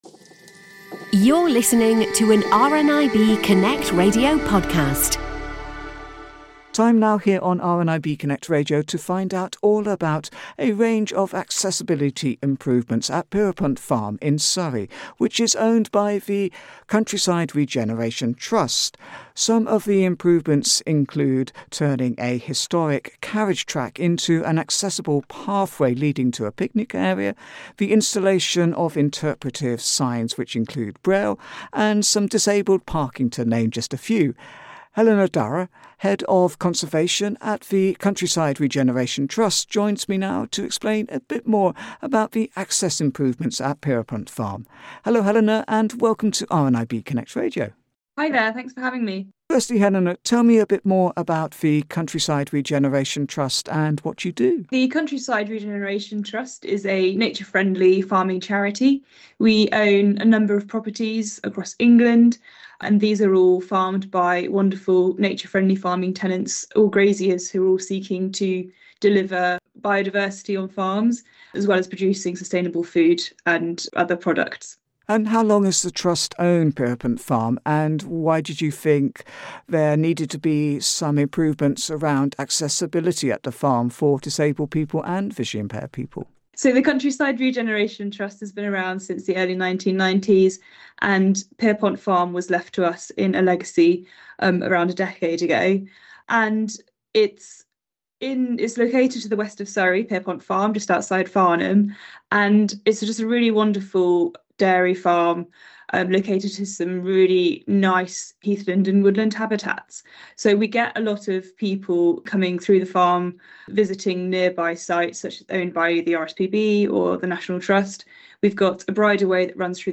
Connect Radio’s